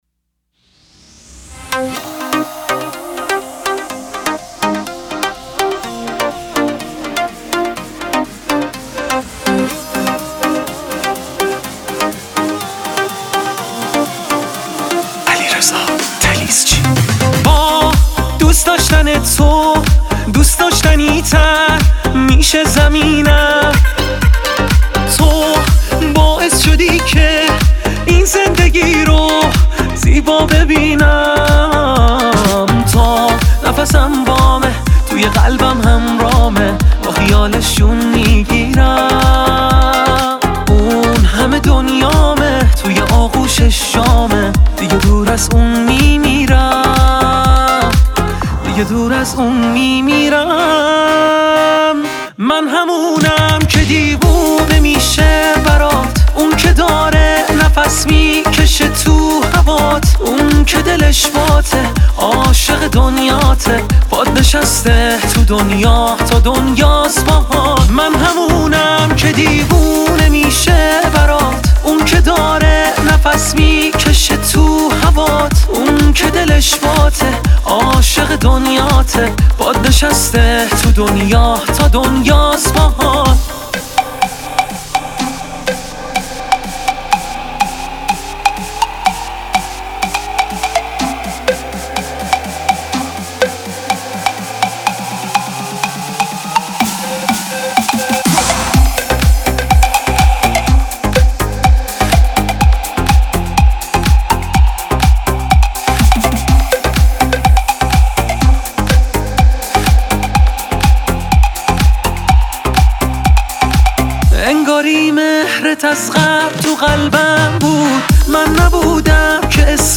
آهنگ تیتراژ